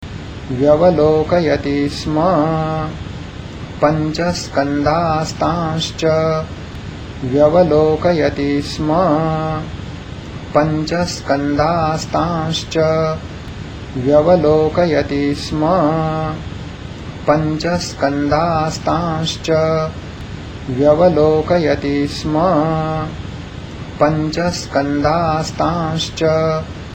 1_3_monk.mp3